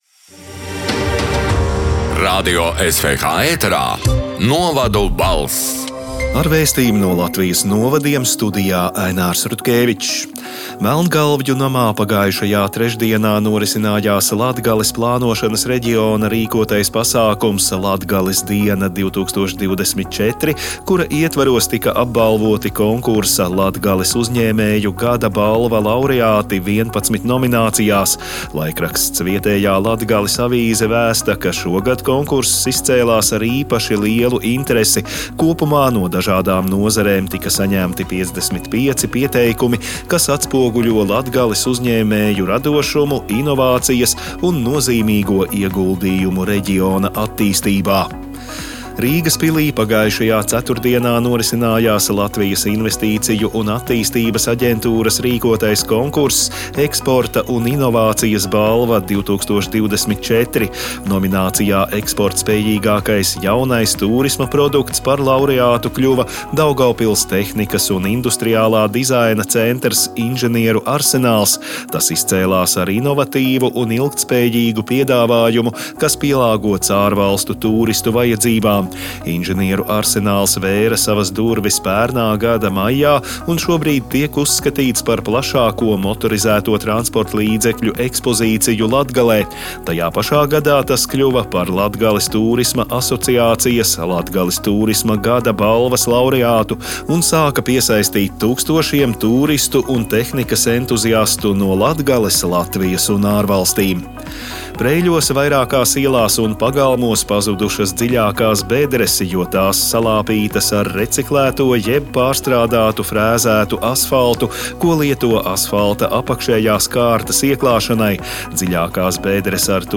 “Novadu balss” 13. decembra ziņu raidījuma ieraksts: